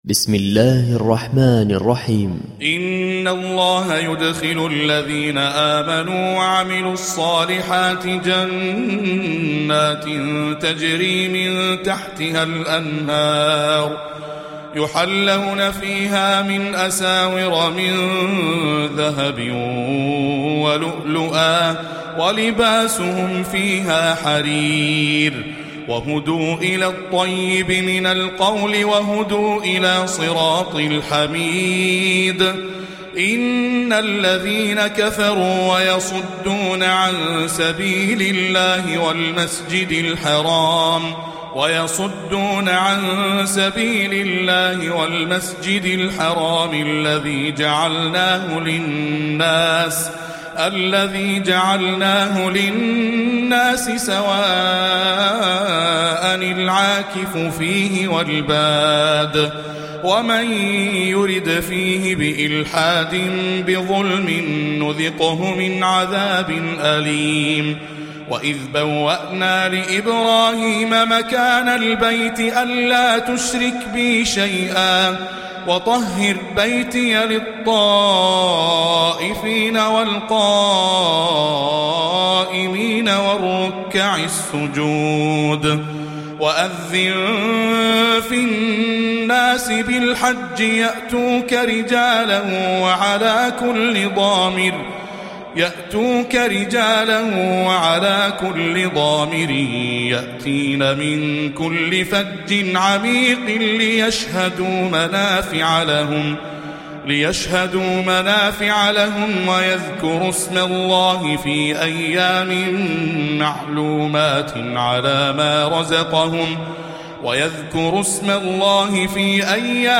تلاوة رائعة ..